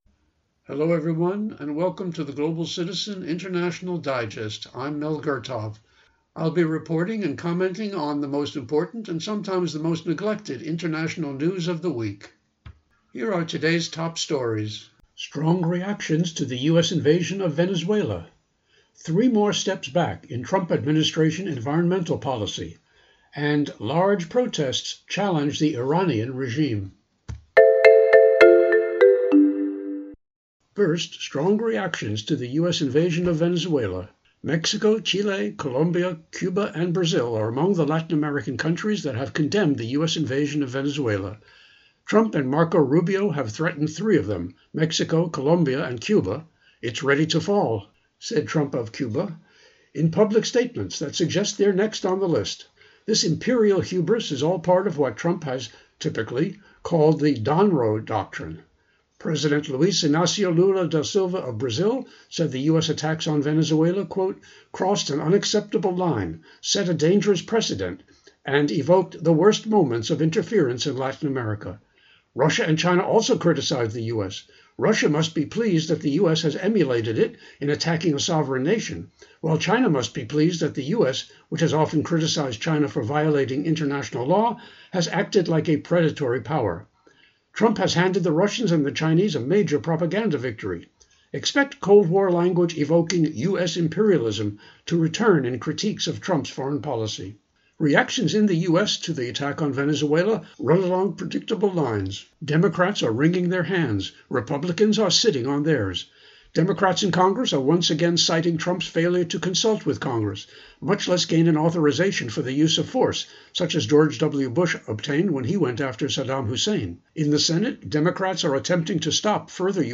Genre(s): Public Affairs